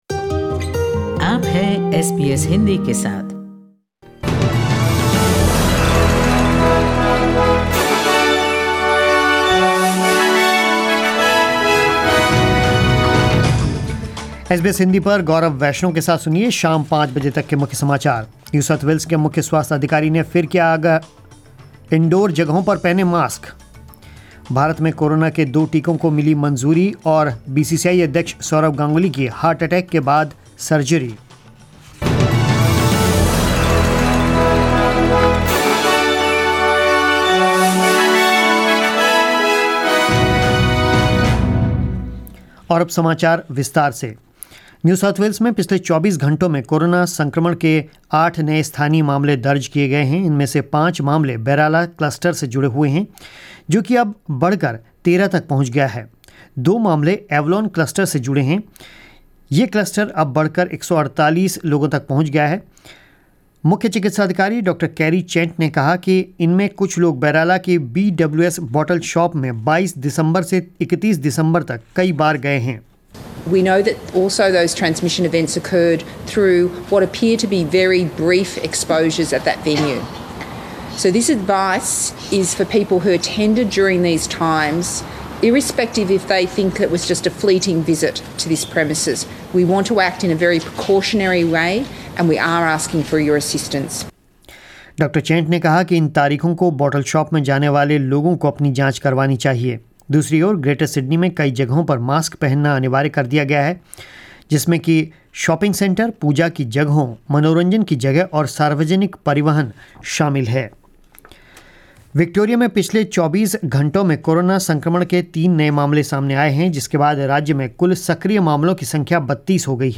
News in Hindi 03 January 2021 ** Two vaccines for coronavirus, Pune-based Serum Institute's Covishield and Bharat Biotech's Covaxin, received emergency approval from the country's drug regulator today. // ** Indoor face masks now mandatory in New South Wales as eight new COVID-19 cases recorded.